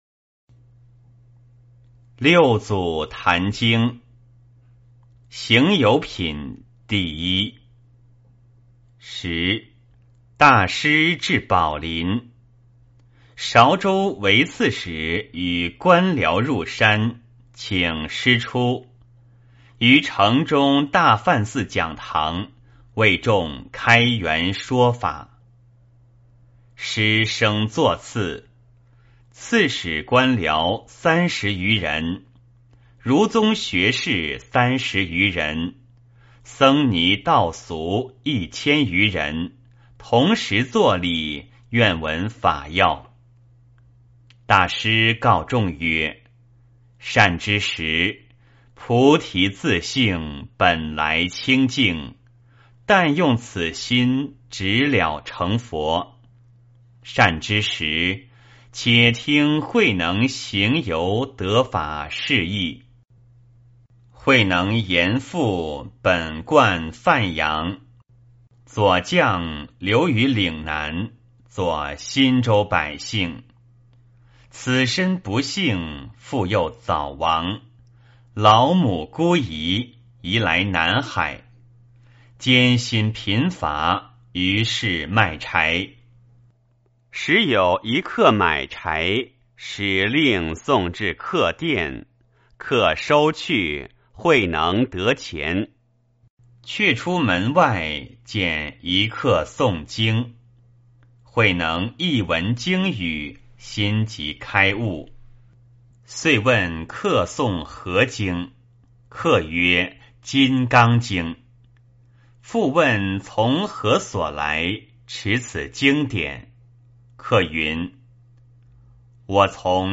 坛经（行由品） - 诵经 - 云佛论坛